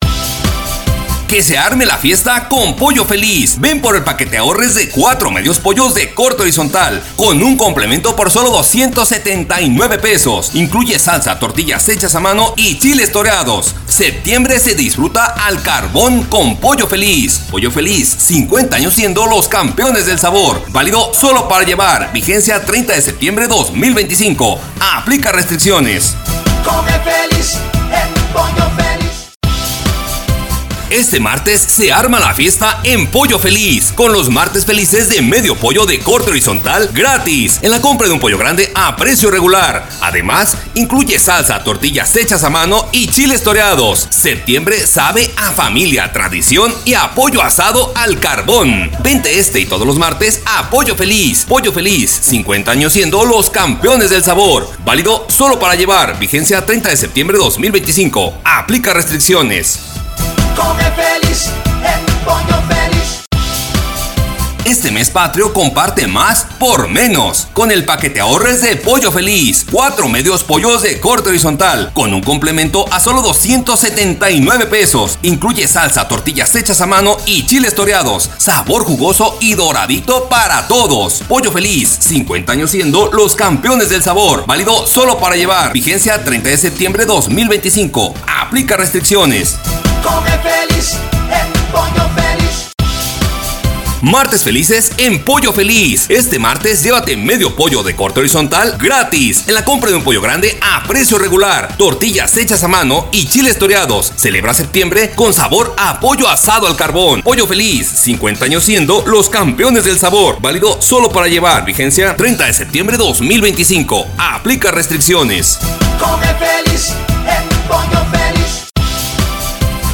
PERIFONEO
Ejemplo de spot